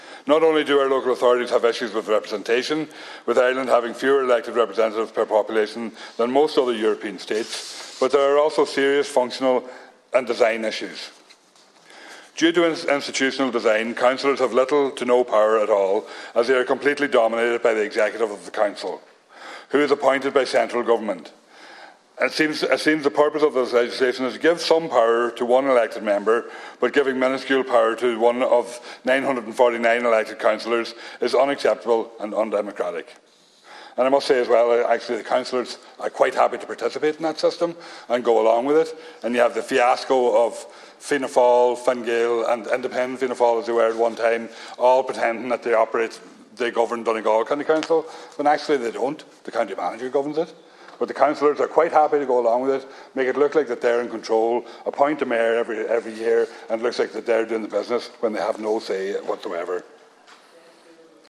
Speaking in the Dail, Deputy Pringle says it appears Councillors are happy to continue operating the current format: